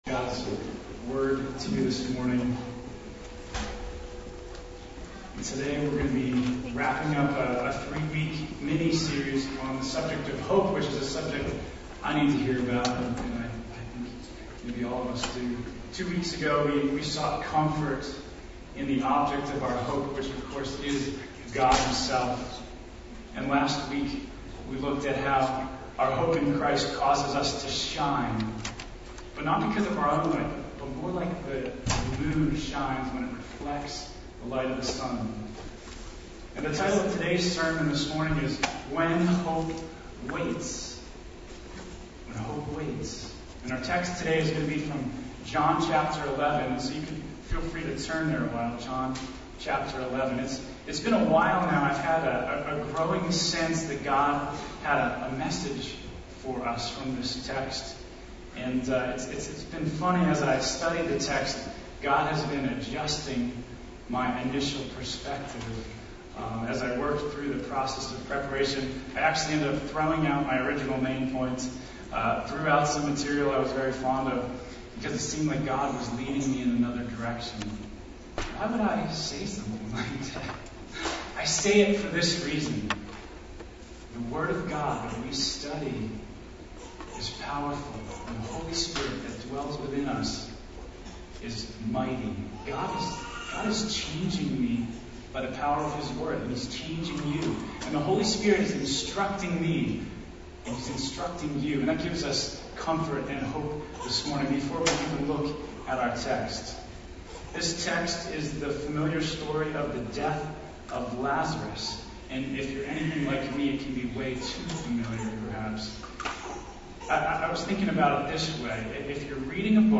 NOTE: There were recording problems at the beginning of the message. Around 10 minutes into the sermon, the audio clears up considerably.